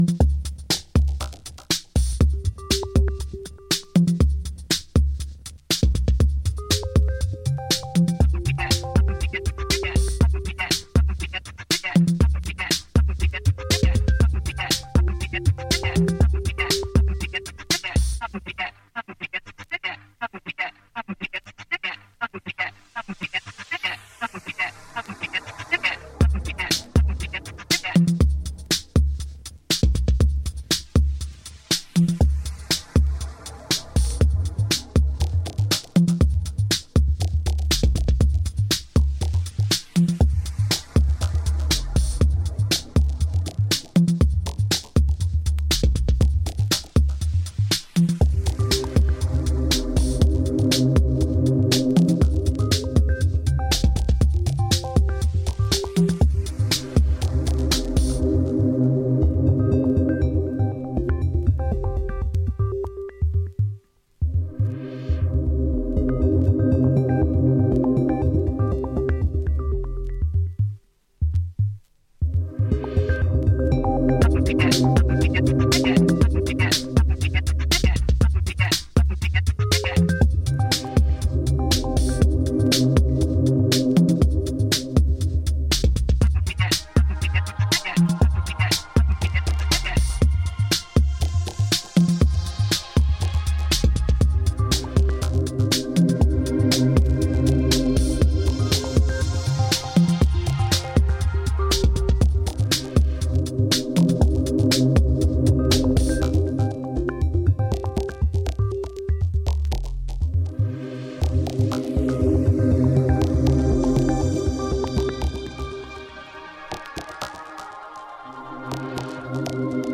Disco Electro House